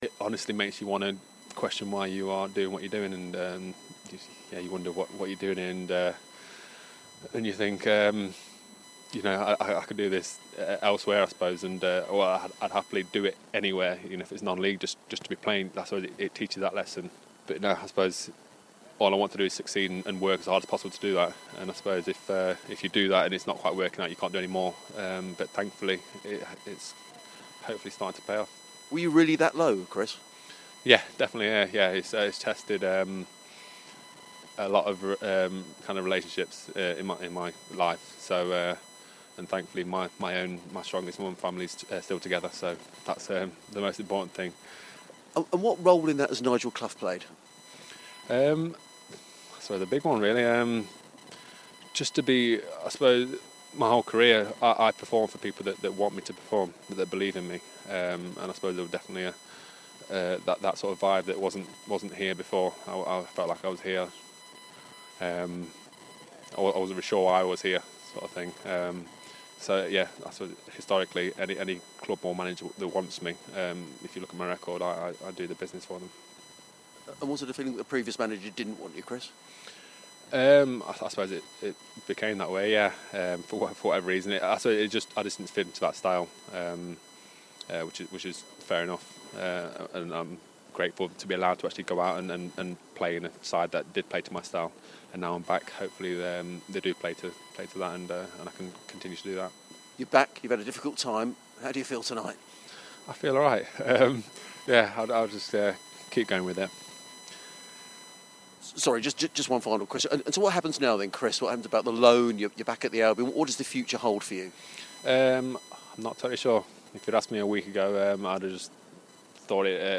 INTERVIEW: Chris O'Grady on his time with Sheffield United and returning to Brighton and Hove Albion.